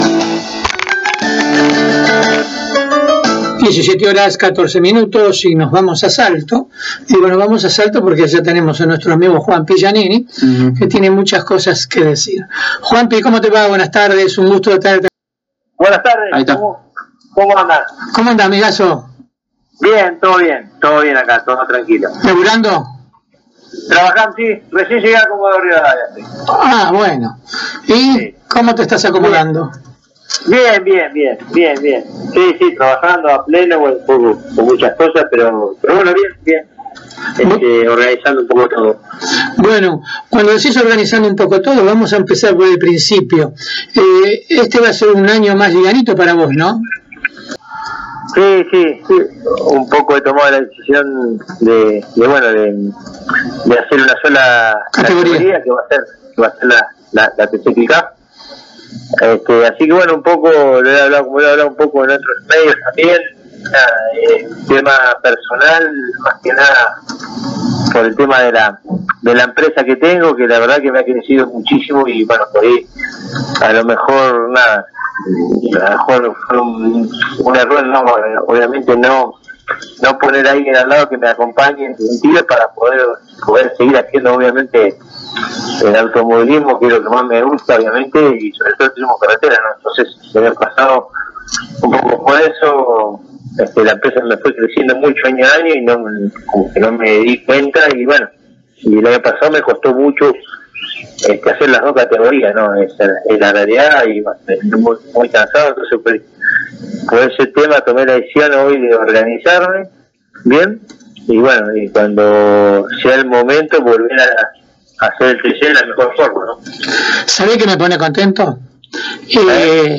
El piloto de Salto, paso por los micrófonos de Pole Position y dio a conocer los motivos por el cual no arrancará compitiendo dentro del Turismo Carretera esta temporada. Por motivos laborales con su empresa personal, Juan Pablo Gianini estará compitiendo por el momento solo en TC Pickup.